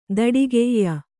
♪ daḍigeyya